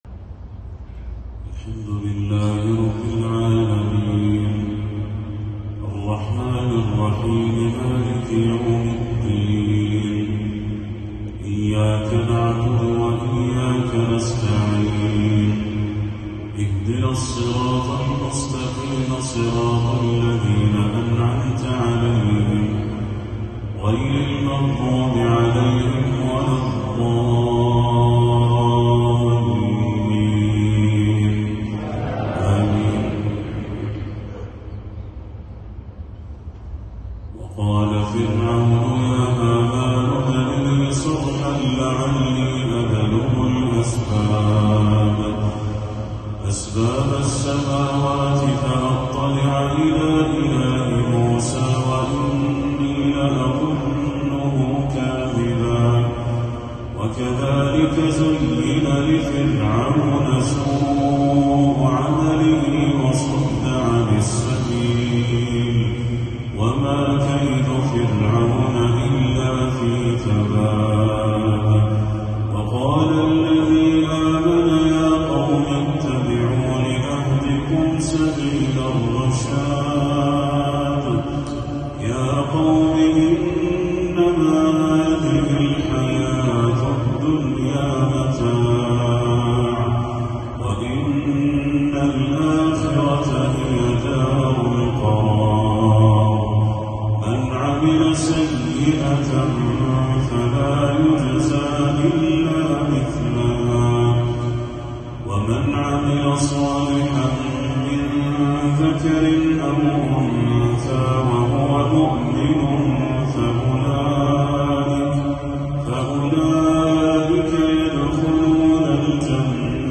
تلاوة لم أجد لها وصفًا!